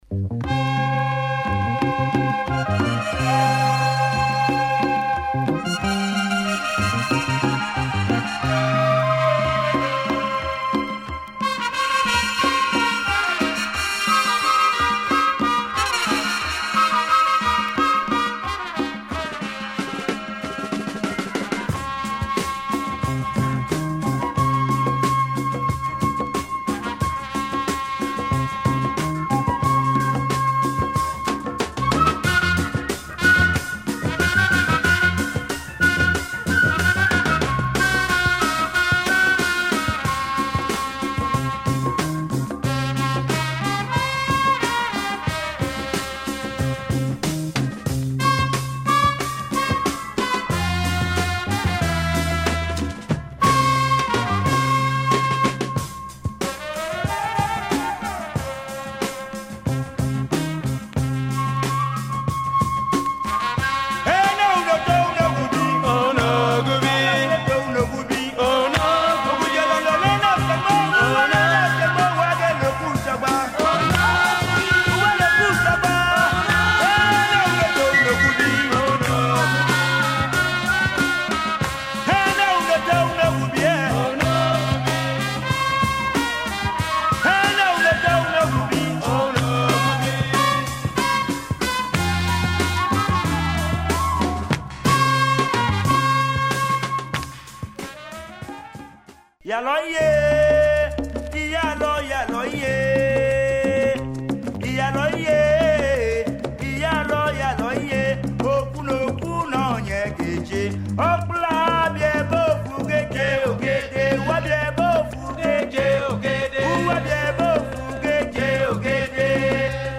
Fantastic afro funk & jazz album
percussions